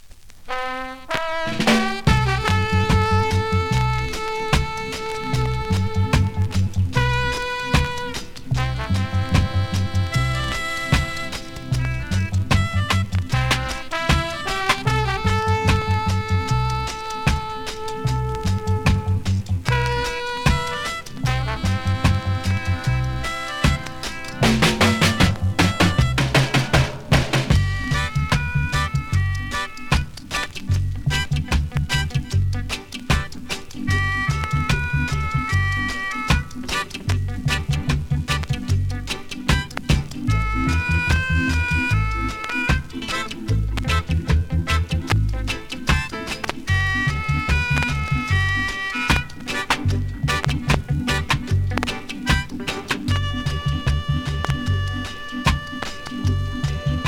NEW IN!SKA〜REGGAE
スリキズ、ノイズかなり少なめの